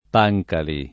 [ p #j kali ]